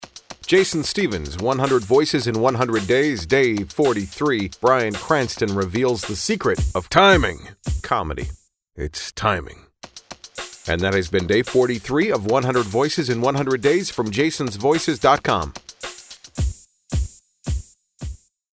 Tags: 100 Voices 100 Days, Bryan Cranston impression, celebrity voice over